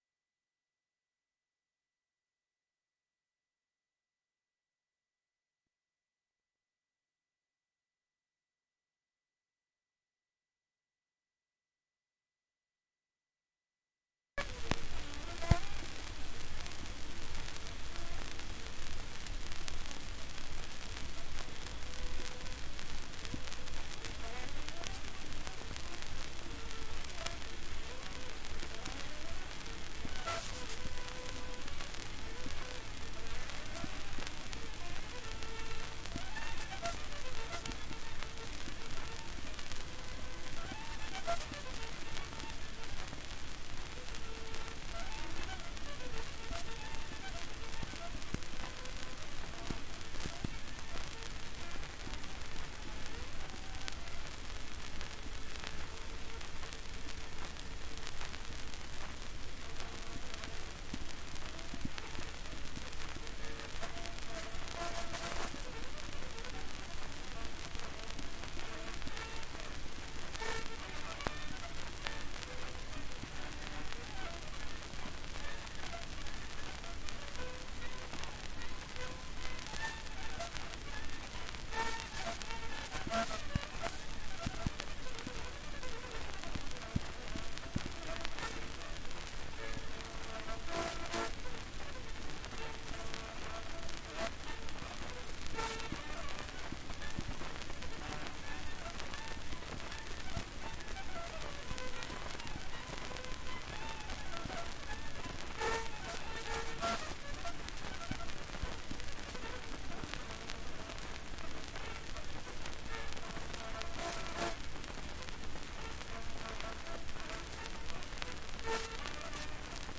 1 10 inch 78rpm shellac disc
J Scott Skinner Violin Solo
S2 Bagpipe Marches: Athole Highlander's Farewell to Loch Katrine; Cameron Highlanders; Inverness Gathering